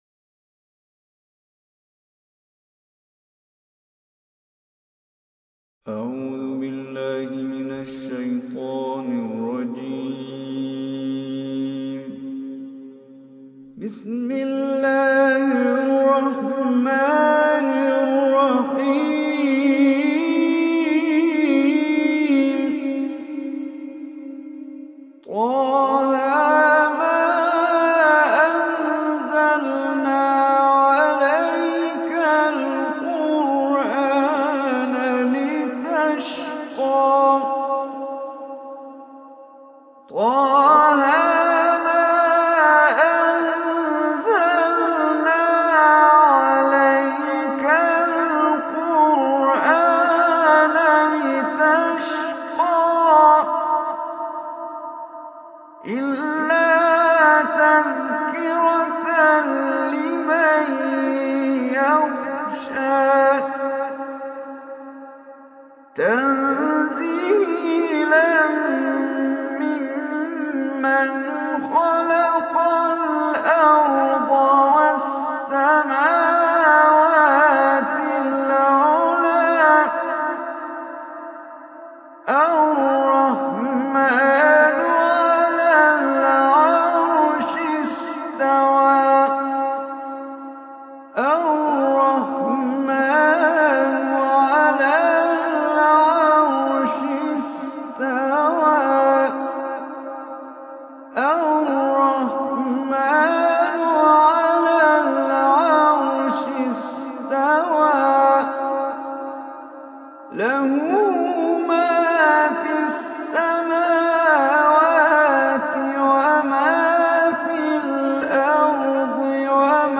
Surah Taha Online Recitation by Omar Hisham Arabi